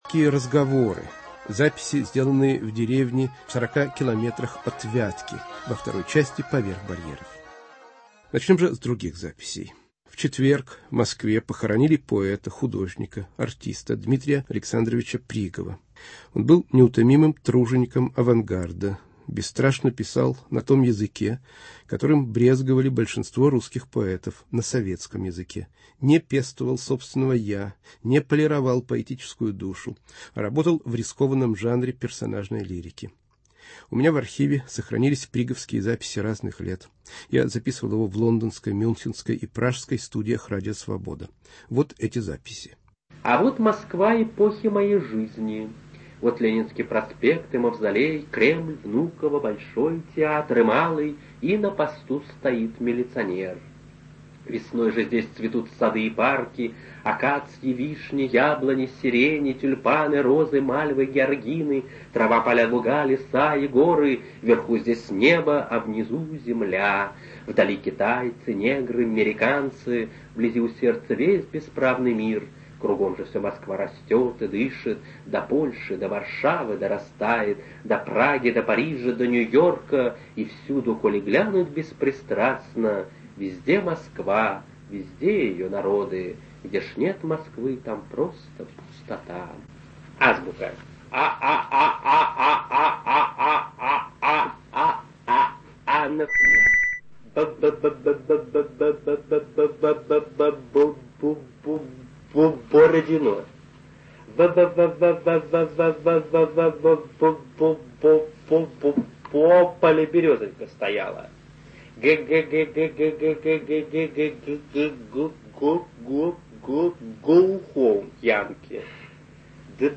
Памяти Д.А.Пригова. Записи разных лет, сделанные в лондонской, мюнхенской и пражской студиях радио "Свобода".